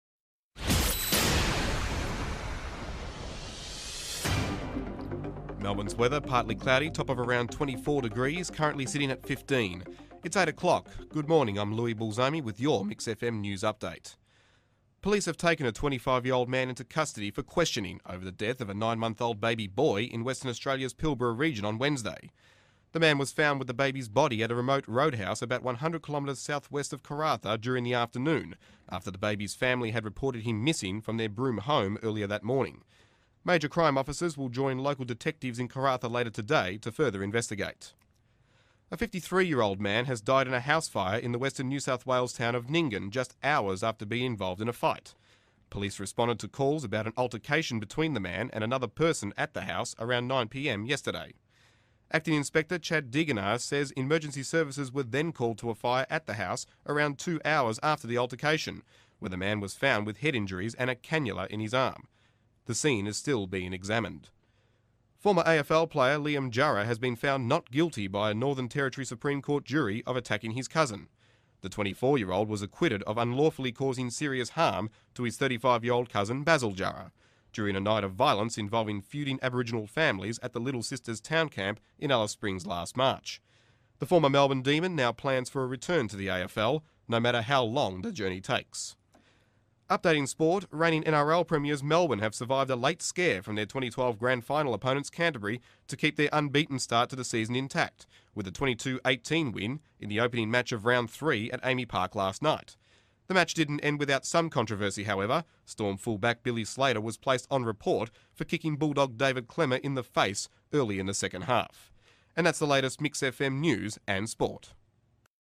Male
News Read
Words that describe my voice are Deep, Strong, Projecting.